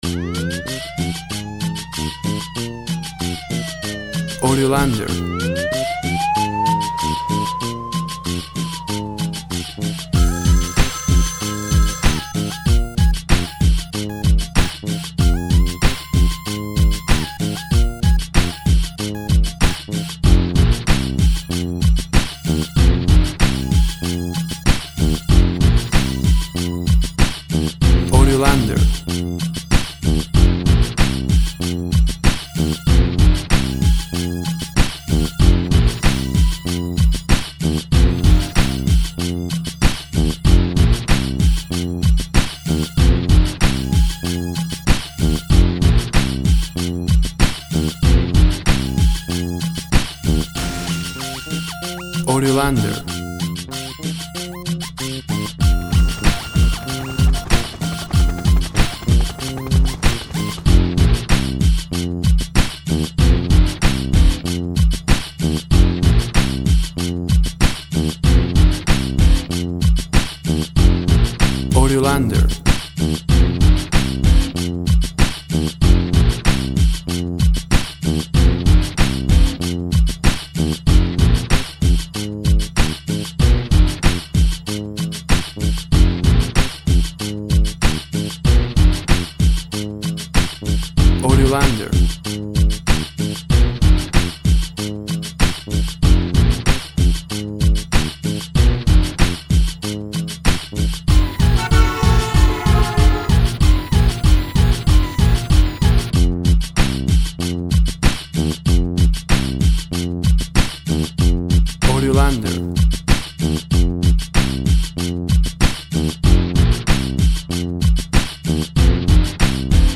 Hard rock kumbiero latín style.
Tempo (BPM) 95